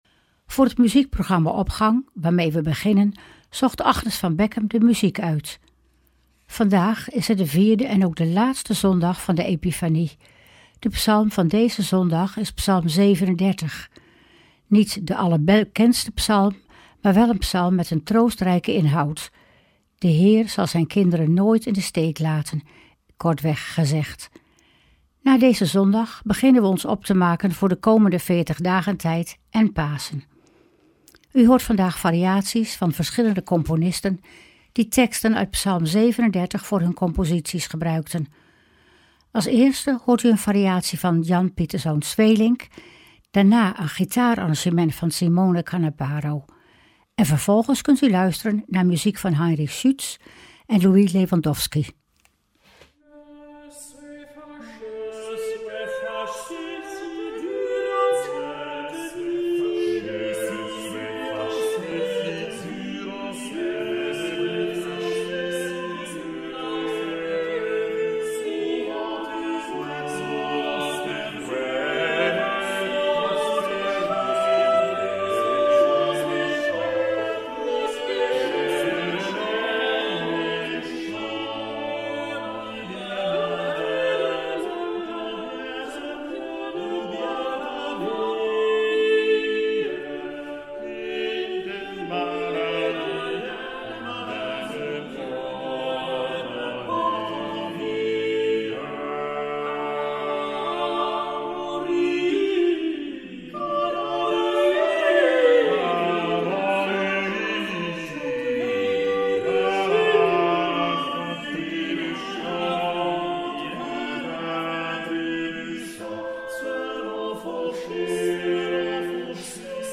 Opening van deze zondag met muziek, rechtstreeks vanuit onze studio.
Instrumentale gitaarzetting